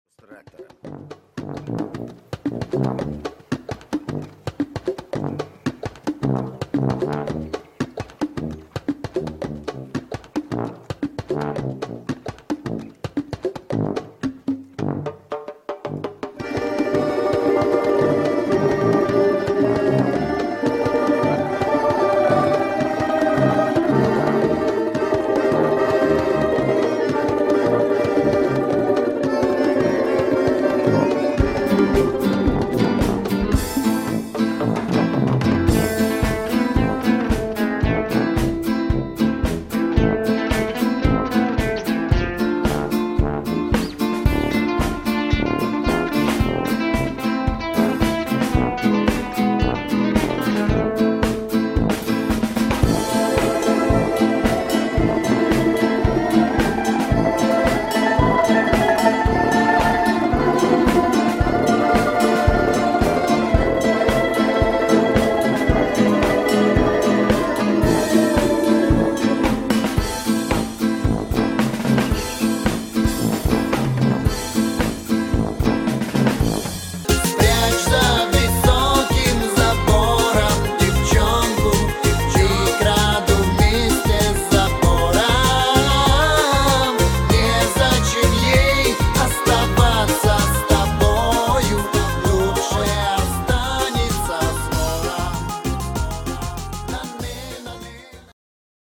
не - там латина, здесь больше русского шансона
не меняя темпа склеил......склейка